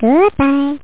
Amiga 8-bit Sampled Voice
bye.mp3